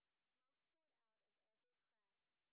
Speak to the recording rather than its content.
sp13_white_snr10.wav